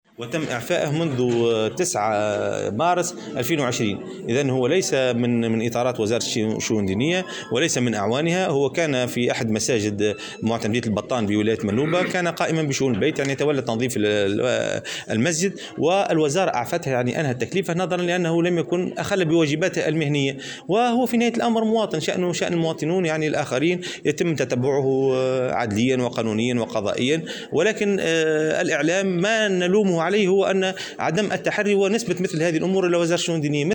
وأضاف في تصريح لمراسل "الجوهرة أف أم" اليوم على هامش افتتاح ندوة وطنية حول "الطفولة رهان المستقبل" أنه لابد من التثبت من الاخبار قبل نشرها.